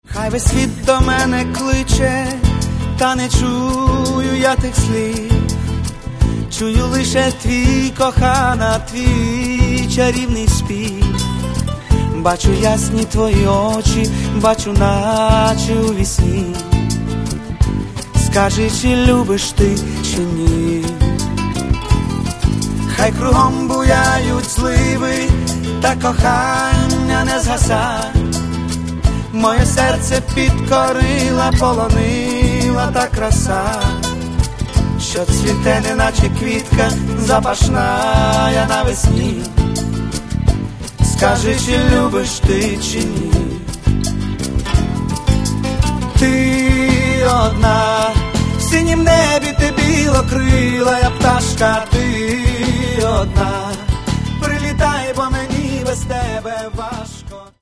Каталог -> Поп (Легкая) -> Лирическая